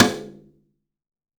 Index of /90_sSampleCDs/AKAI S6000 CD-ROM - Volume 5/Brazil/SURDO